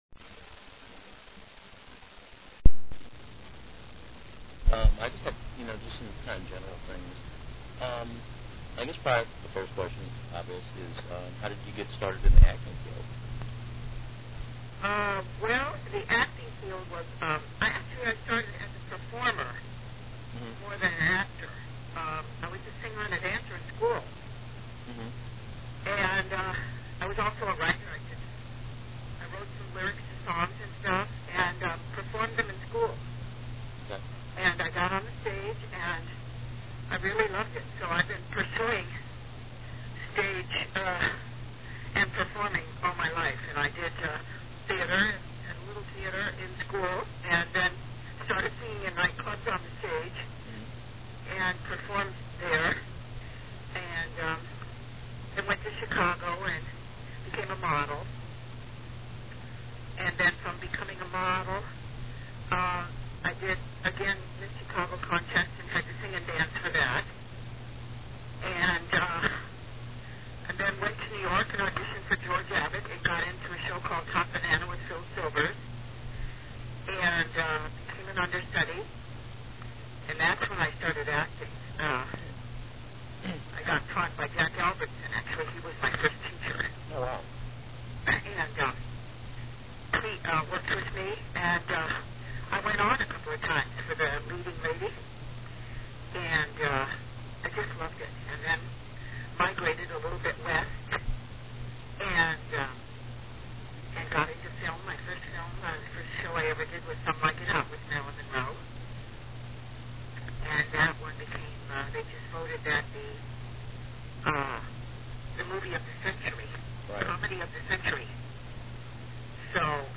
Grace Lee Whitney Interview MP3 Format - 30 Mins - (4.5M) ASF Format (Streaming) - 30 Mins - (3.5M) * *For ASFs, you will need the latest Microsoft Media Player w/The MPEG 4 V3 Compression Codec.